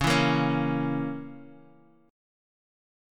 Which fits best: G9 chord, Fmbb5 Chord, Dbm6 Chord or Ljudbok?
Dbm6 Chord